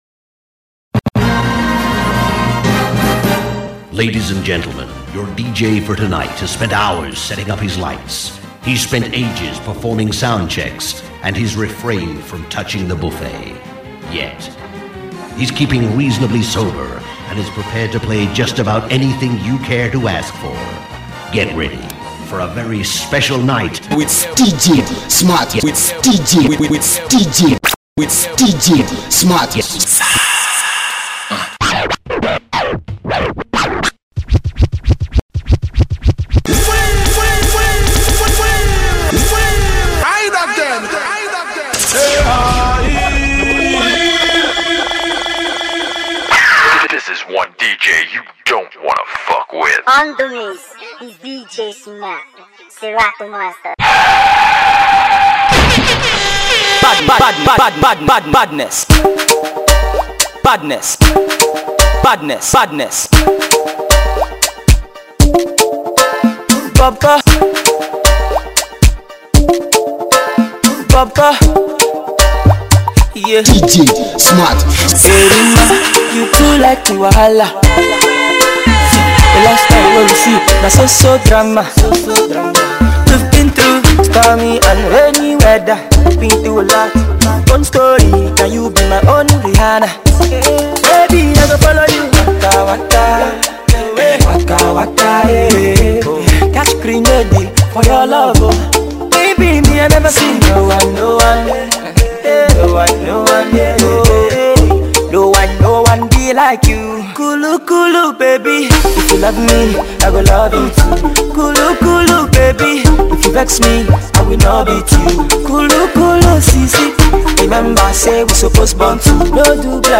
Hot & Banging club mix